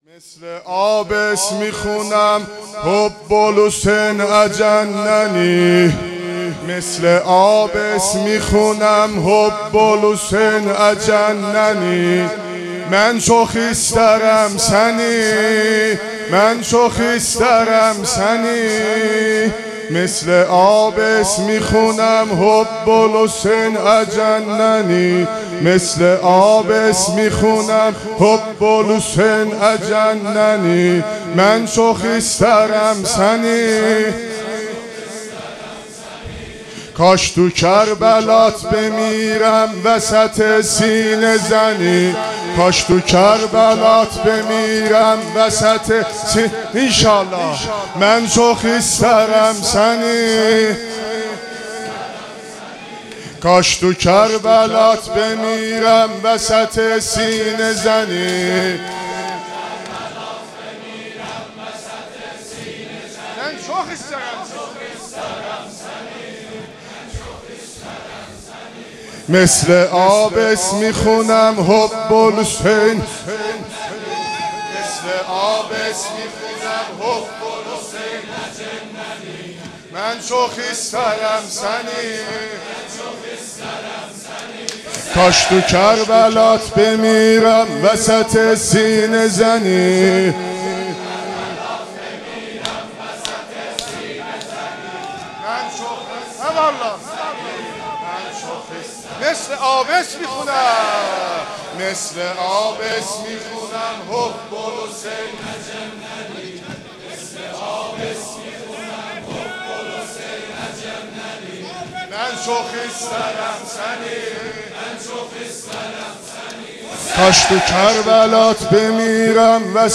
دودمه